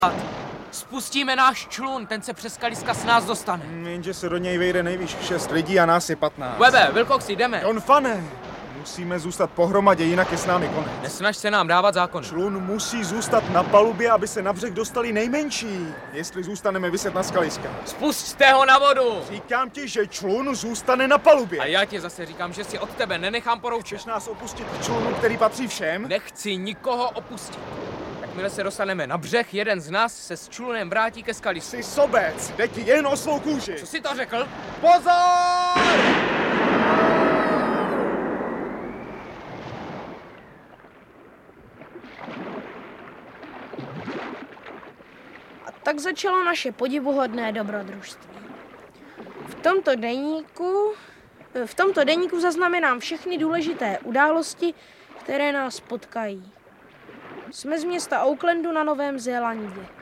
Audiobook
Read: Marek Eben